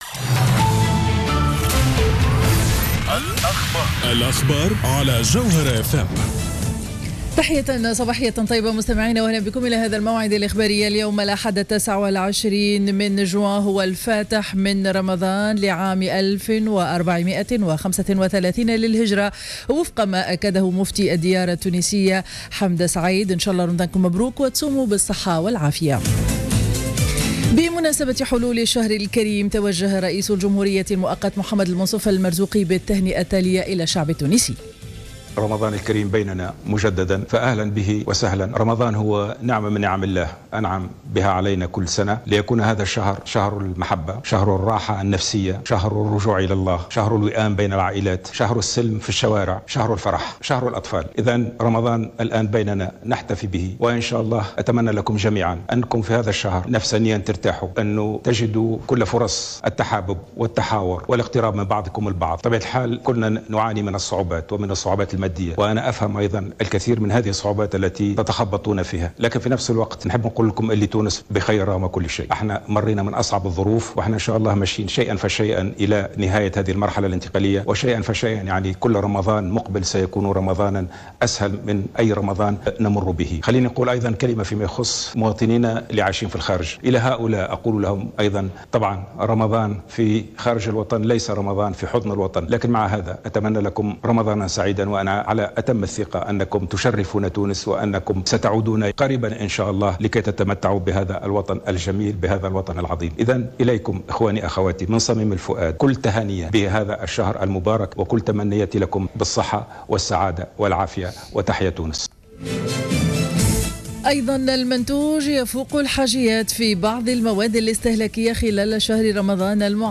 نشرة أخبار السابعة صباحا ليوم الأحد 29-06-14